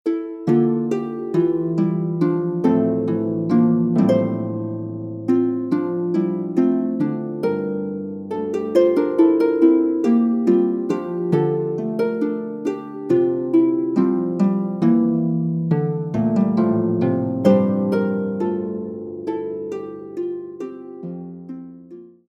Music for an Imaginary Harp